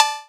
Perc 11.wav